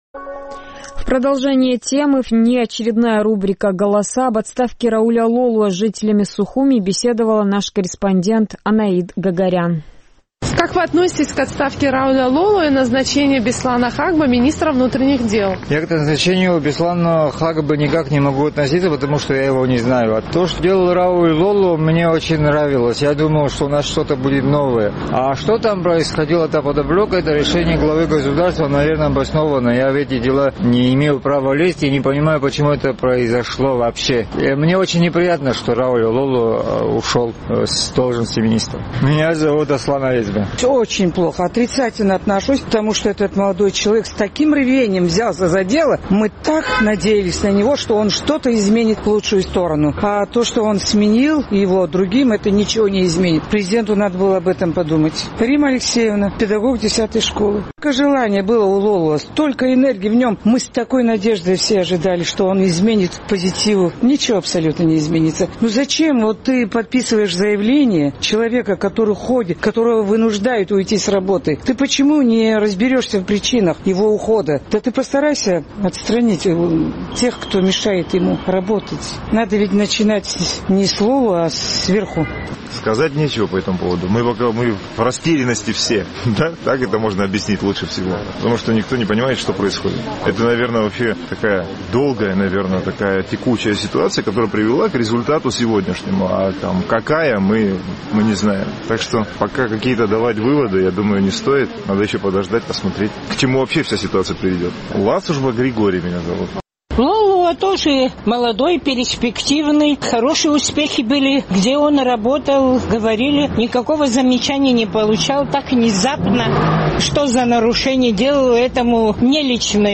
Наш сухумский корреспондент побеседовал с жителями абхазской столицы по поводу отставки министра внутренних дел Рауля Лолуа и назначения на этот пост Беслана Хагба.